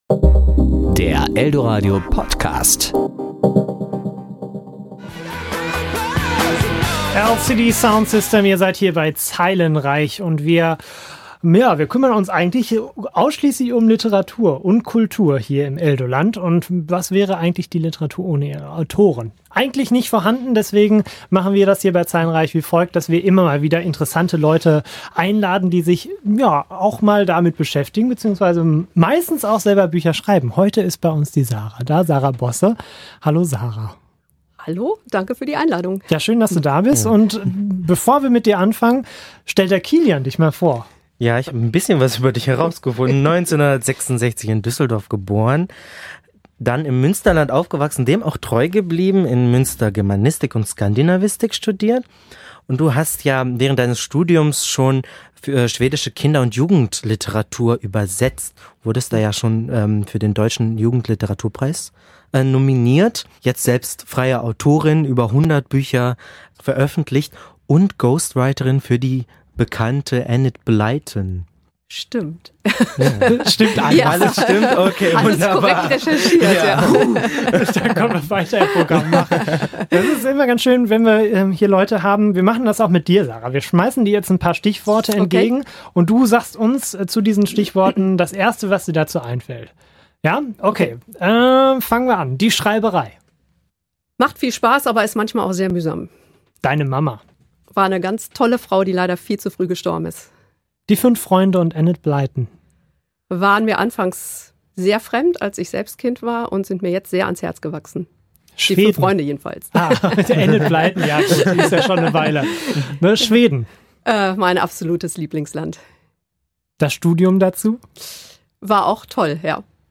Serie: Interview Ressort: Literatur Sendung: Zeilenreich